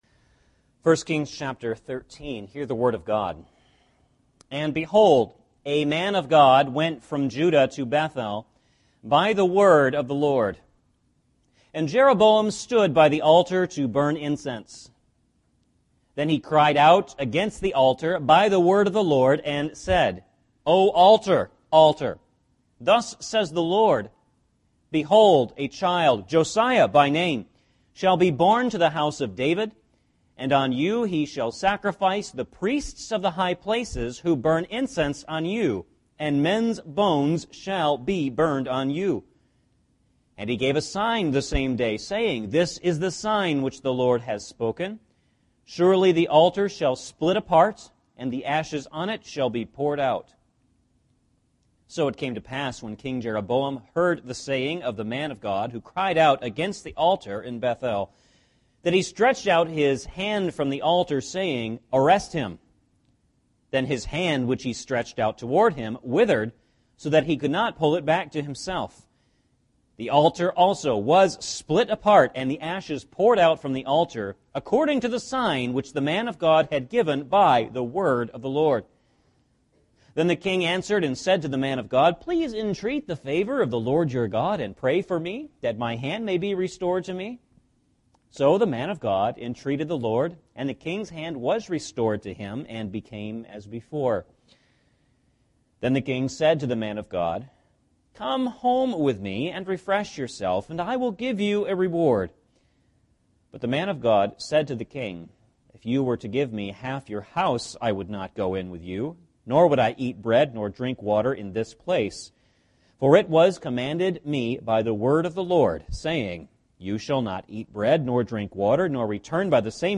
1 Kings Passage: 1 Kings 13 Service Type: Sunday Evening Service « Young Men